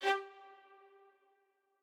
strings1_5.ogg